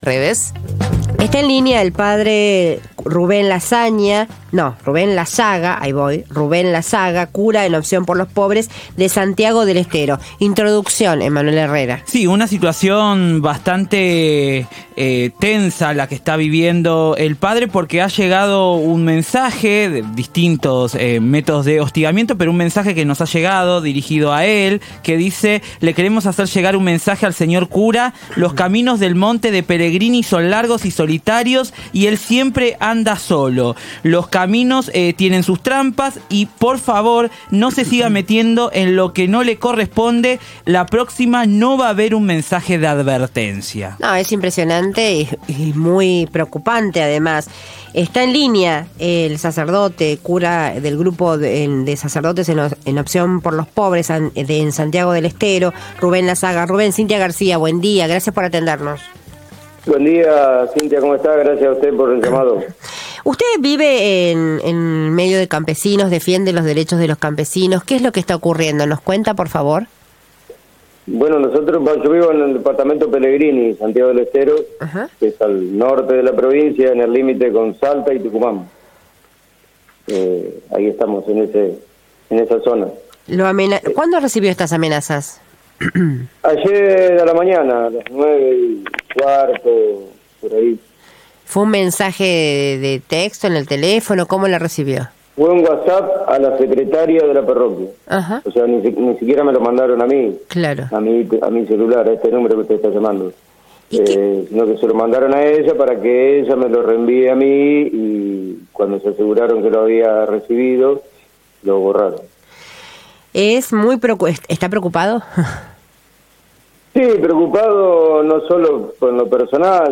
En comunicación con AM750, el cura sostuvo que la amenaza no es personal sino que se trata de un ataque contra el movimiento campesino y las comunidades que se organizan para exigir sus derechos: el acceso a la tierra, salud, educación y justicia.